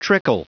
Prononciation du mot trickle en anglais (fichier audio)
Prononciation du mot : trickle